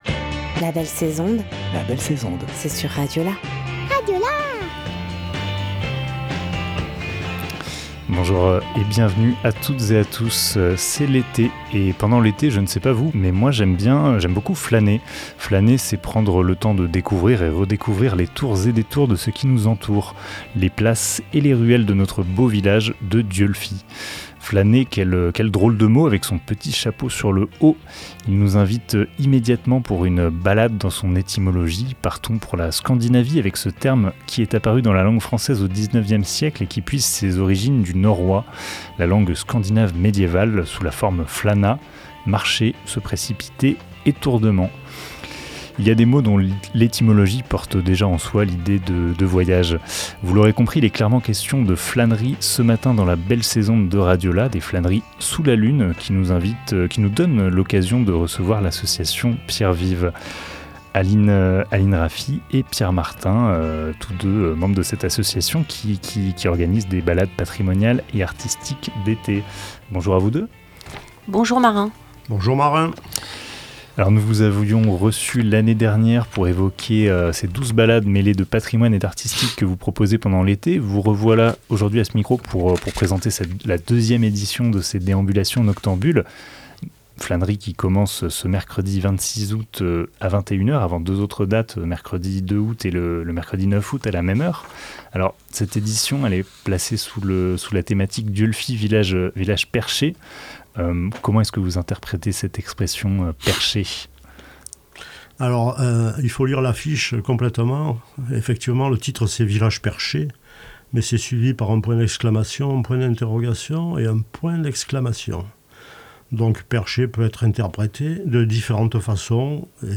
26 juillet 2023 15:09 | Interview, la belle sais'onde